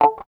74 GTR 4  -L.wav